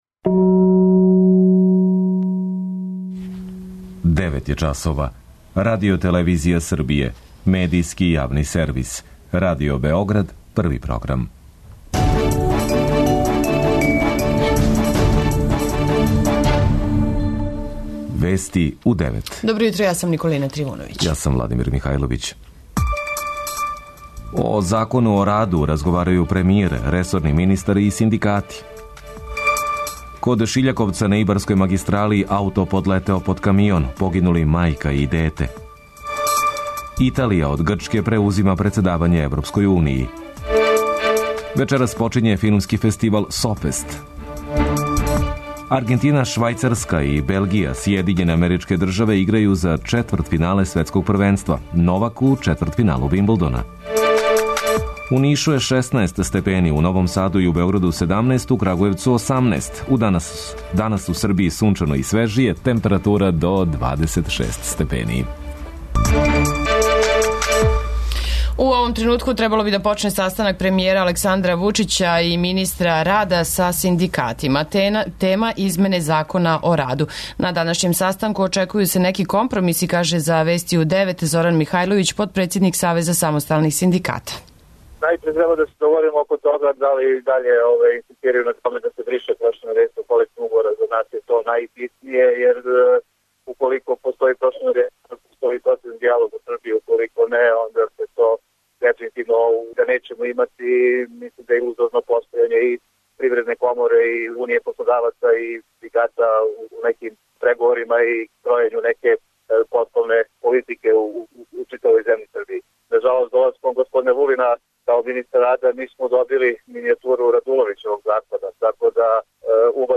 Вести у 9 | Радио Београд 1 | РТС